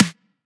edm-snare-52.wav